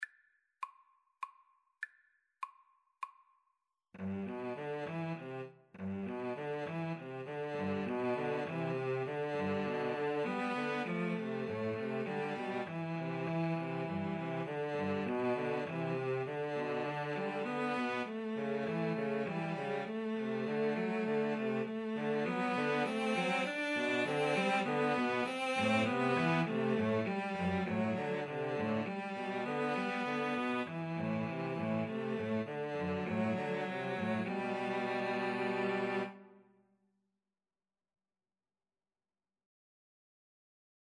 17th-century English folk song.
G major (Sounding Pitch) (View more G major Music for Cello Trio )
Moderato
Cello Trio  (View more Easy Cello Trio Music)